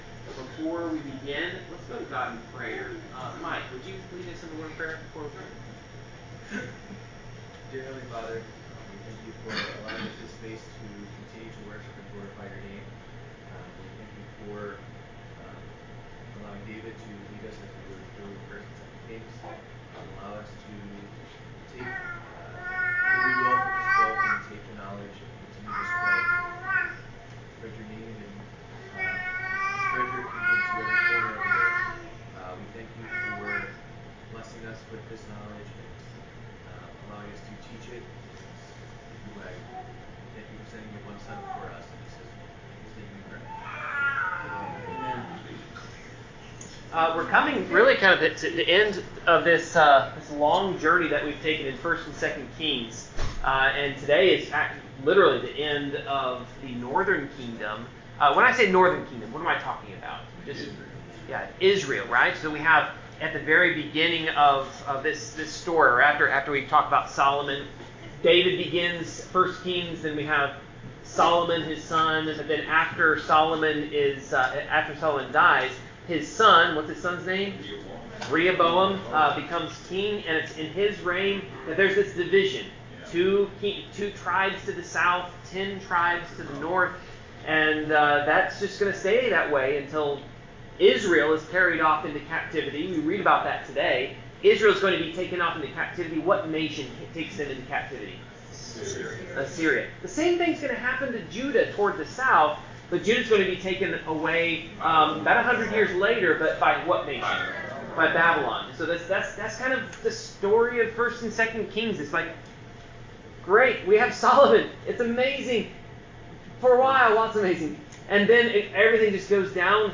Bible class: 2 Kings 17
Service Type: Bible Class Topics: Captivity , Consequences of Sin , God's Instruction , God's Sovereignty , Idolatry , Israel , Judah , Prophecy , Sin , Spiritual Warfare , Trusting in God , Warnings from God « Bible class